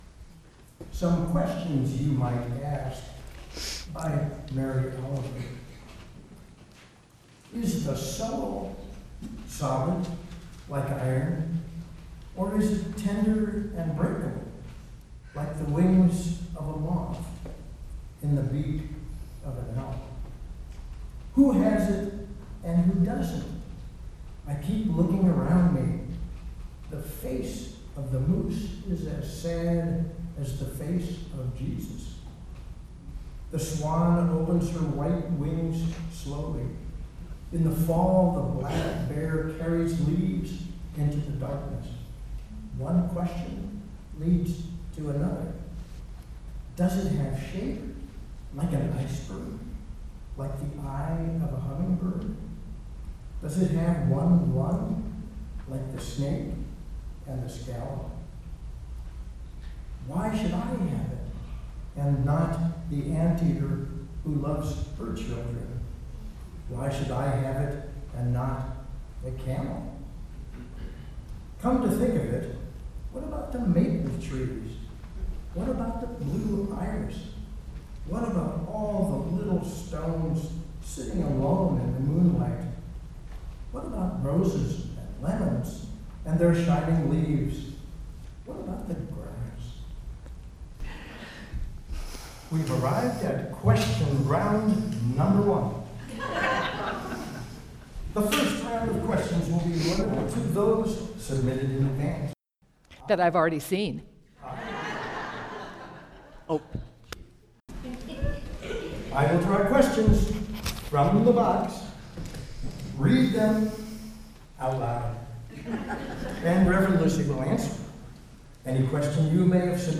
Sermon-What-is-your-Burning-Question.mp3